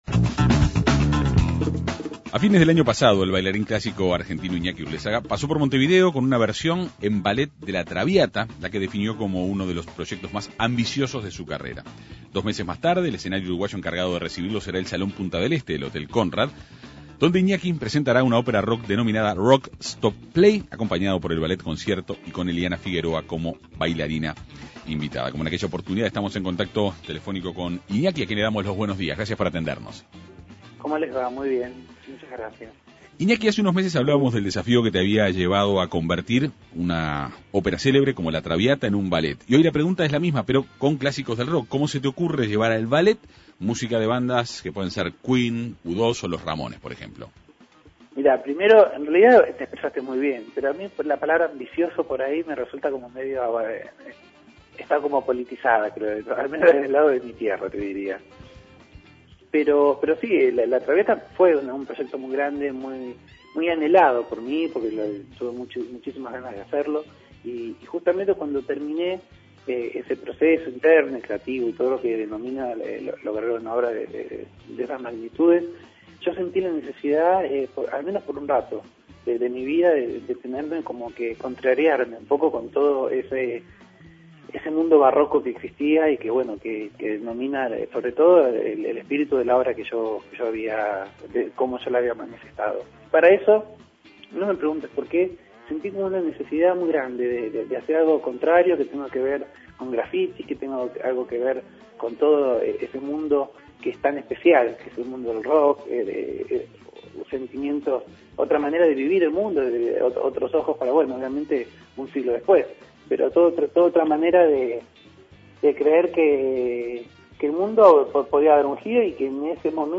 La Segunda Mañana de En Perspectiva conversó con el artista.